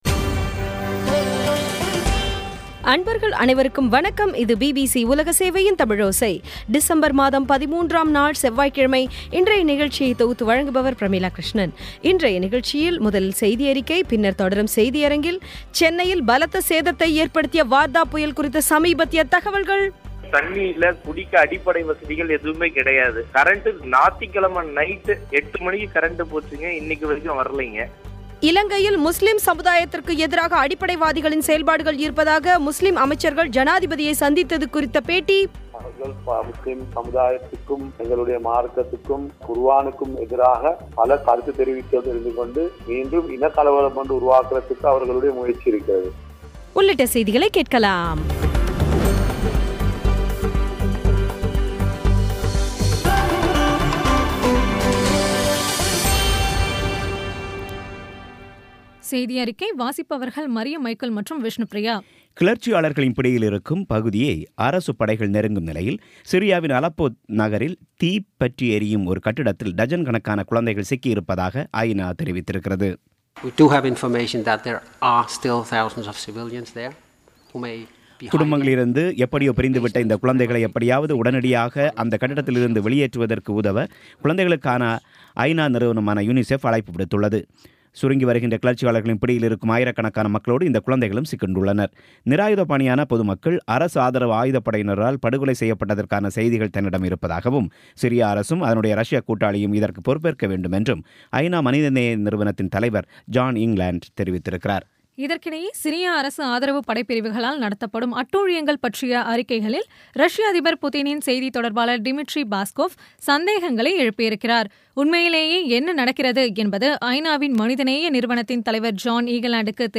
இன்றைய நிகழ்ச்சியில் முதலில் செய்தியறிக்கை, பின்னர் தொடரும் செய்தியரங்கில் சென்னையில் பலத்த சேதத்தை ஏற்படுத்திய வார்தா புயல் குறித்த சமீபத்திய தகவல்கள் இலங்கையில் முஸ்லீம் சமுதாயத்திற்கு எதிராக அடிப்படைவாதிகளின் செயல்பாடுகள் இருப்பதாக முஸ்லீம் அமைச்சர்கள் ஜனாதிபதியை சந்தித்தது கூறியுள்ளது குறித்த செய்தி உள்ளிட்ட செய்திகளை கேட்கலாம்